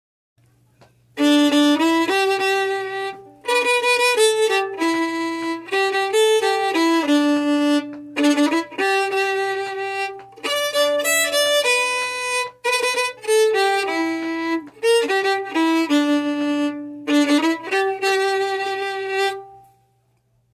Key: G
Form: Song, 4/4 time
Source:Trad.
Region: USA, Appalachia?